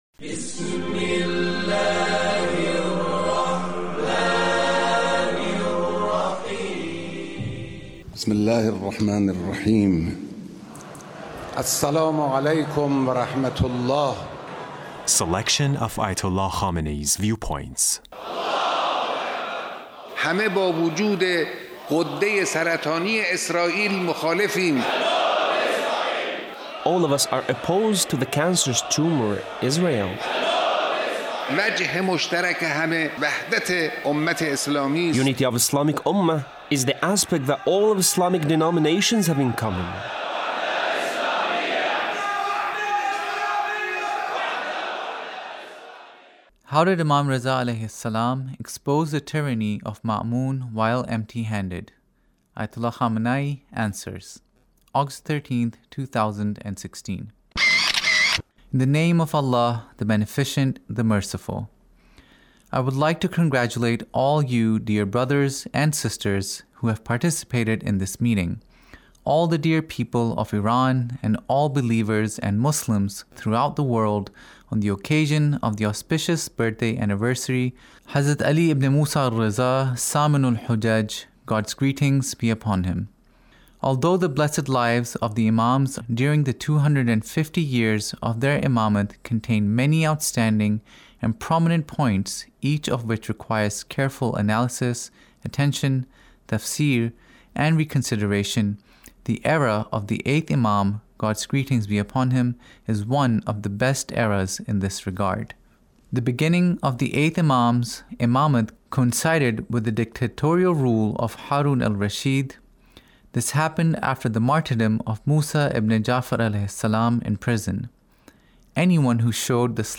Leader's Speech (1874)
Leader's Speech on Imam Reza 's Birthday